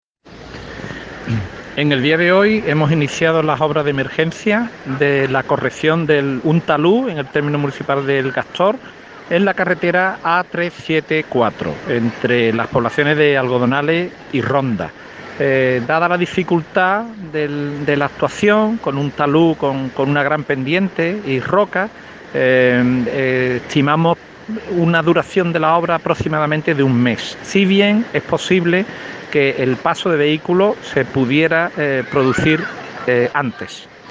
Federico Fernández, delegado territorial de Fomento: